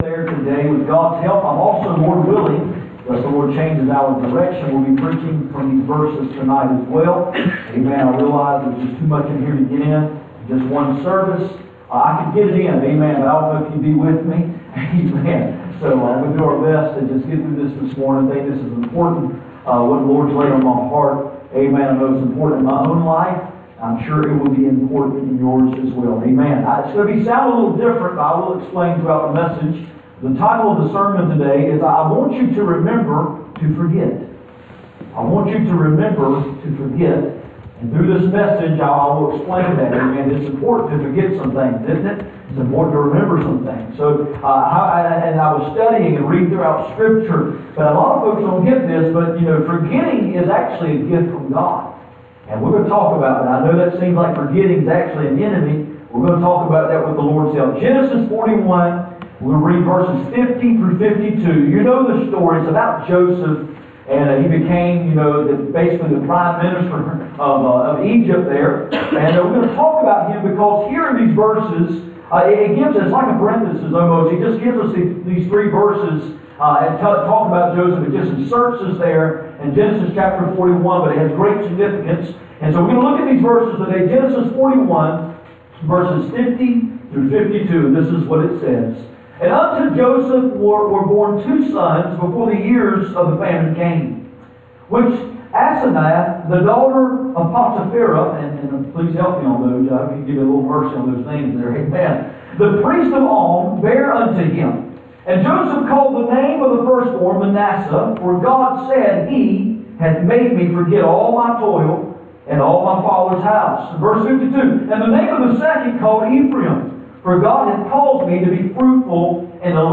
None Passage: Genesis 41:50-52 Service Type: Sunday Morning %todo_render% « The dawning of indestructable Joy Remember to Forget